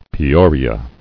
[Pe·or·i·a]